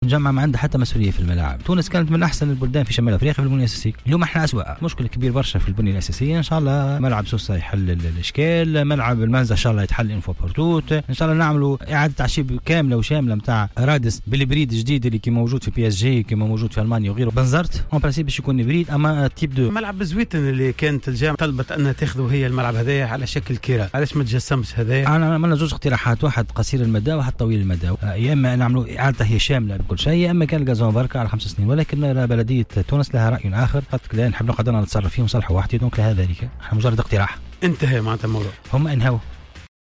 أكد رئيس الجامعة التونسية لكرة القدم وديع الجريء ضيف حصة "قوول" أن تردي حالة الملاعب والمنشآت الرياضية في تونس أصبح أمرا مقلقا مضيف أنه يجب البحث عن طريقة جديدة للتعامل مع ملف صيانة المنشآت الرياضية والحفاظ على سلامتها.